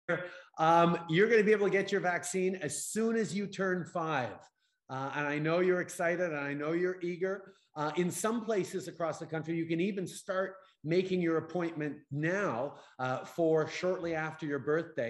Justin Trudeau spricht zu einem 4 jährigen Kind.